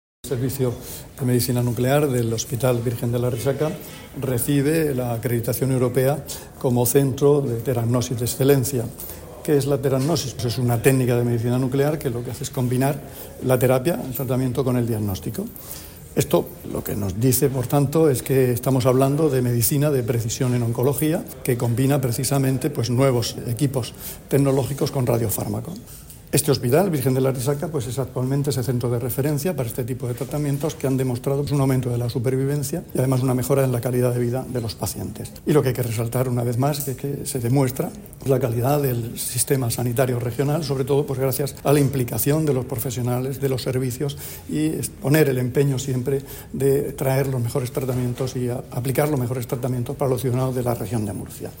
Declaraciones del consejero de Salud, Juan José Pedreño, sobre la acreditación del Servicio de Medicina Nuclear del hospital Virgen de la Arrixaca como Centro de Teragnosis de Excelencia.